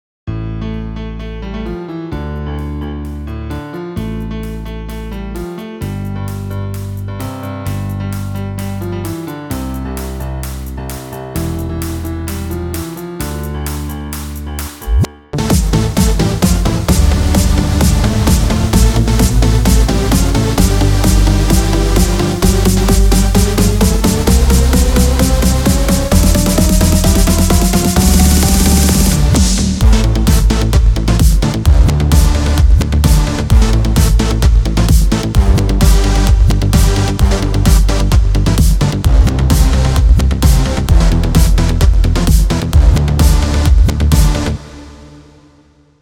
אתה על ימאהה אם הבנתי נכון?